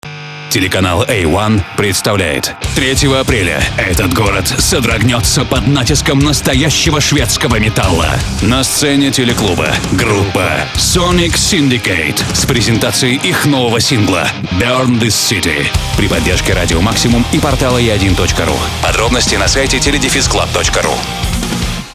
Информационный промо-ролик (2-3 муз. положки + спец эффекты)